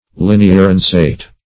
Search Result for " linearensate" : The Collaborative International Dictionary of English v.0.48: Linearensate \Lin`e*ar*en"sate\ (l[i^]n`[-e]*[~e]r*[e^]n"s[asl]t), a. (Bot.) Having the form of a sword, but very long and narrow.